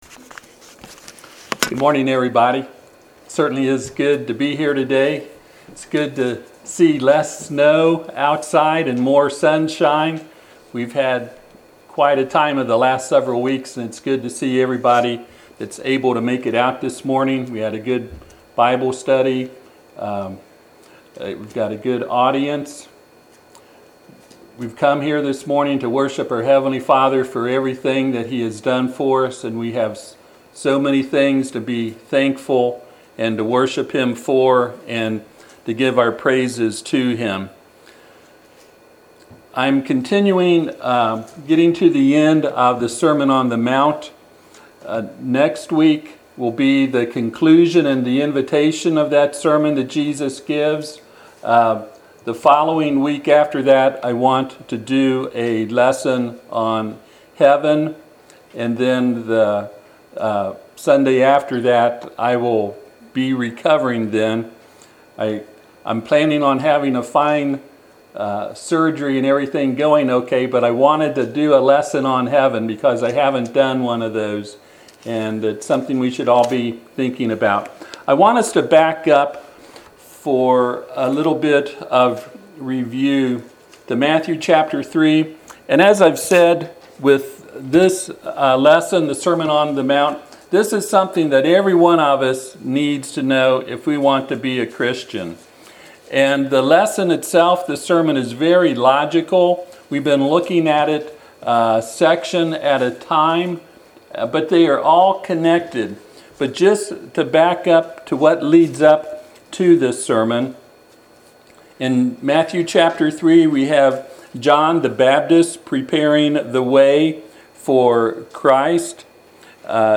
Passage: Matthew 7:1-12 Service Type: Sunday AM